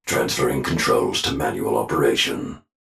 diag_gs_titanRonin_embark_06.wav